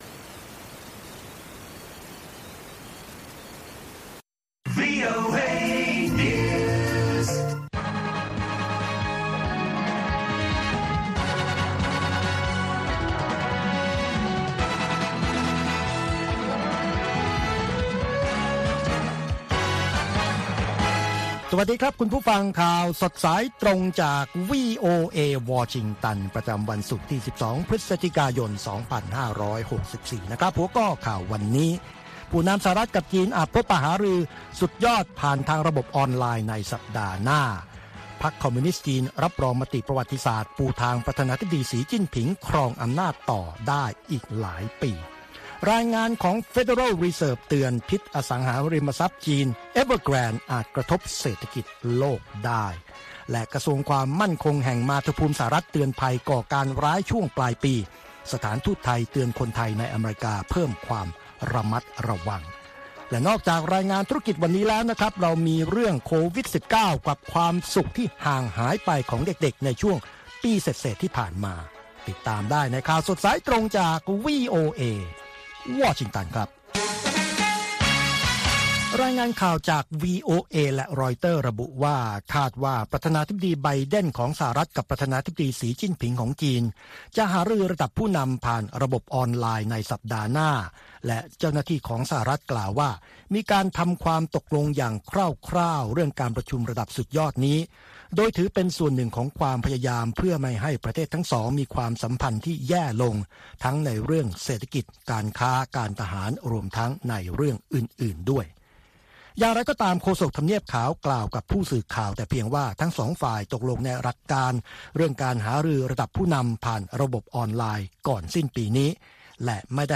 ข่าวสดสายตรงจากวีโอเอ ภาคภาษาไทย ประจำวันศุกร์ที่ 12 พฤศจิกายน 2564 ตามเวลาประเทศไทย